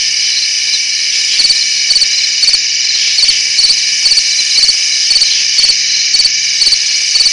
Crickets Sound Effect
Download a high-quality crickets sound effect.
crickets.mp3